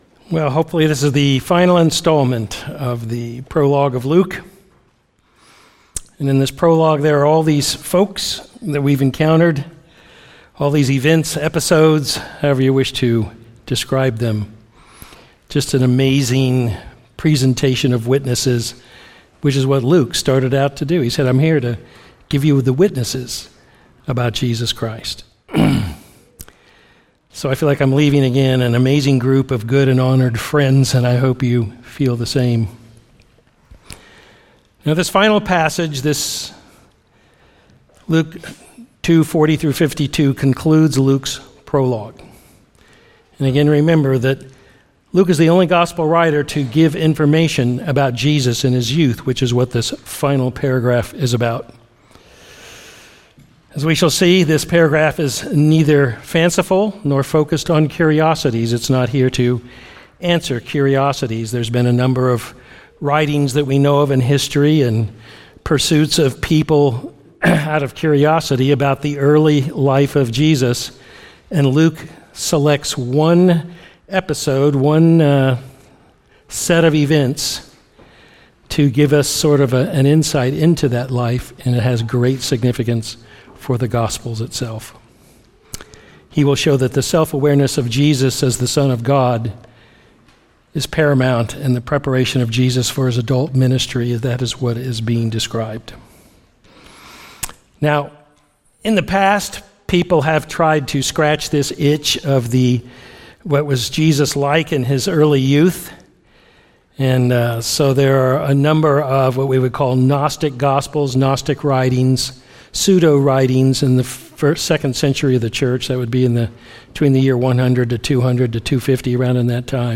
Sermons
Sermons from New Covenant Christian Fellowship: Greenville, SC